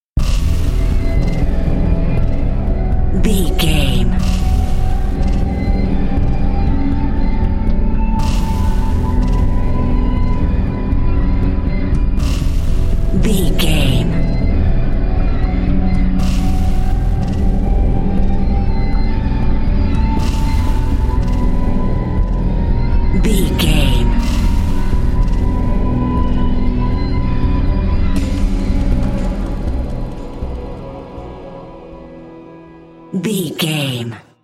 Thriller
Aeolian/Minor
piano
synthesiser
percussion
tension
ominous
dark
suspense
haunting
creepy